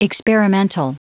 Prononciation du mot experimental en anglais (fichier audio)
Prononciation du mot : experimental